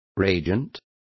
Complete with pronunciation of the translation of reagents.